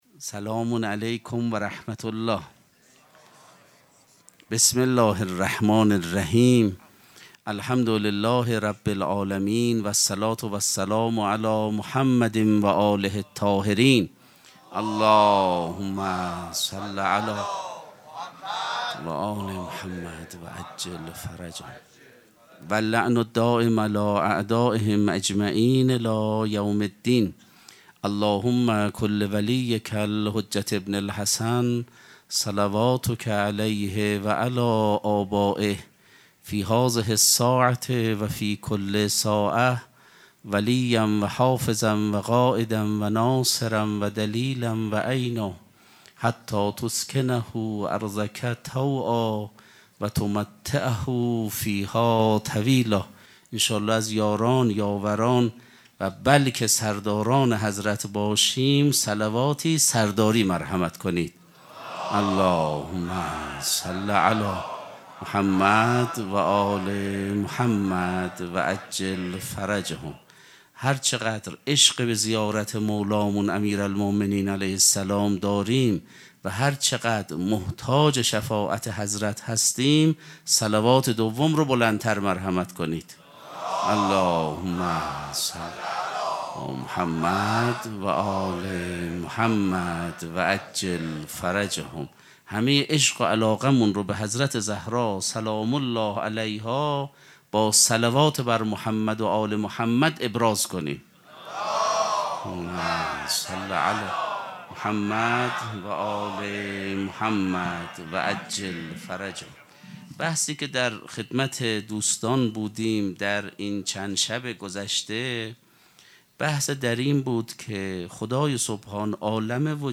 سخنرانی
شب پنجم مراسم هشت شب عاشقی ماه رمضانشنبه ۱۱ فروردین ۱۴۰۳ | ۱۹ رمضان ۱۴۴۵‌‌‌‌‌‌‌‌‌‌‌‌‌هیئت ریحانه الحسین سلام الله علیها
sokhanrani.mp3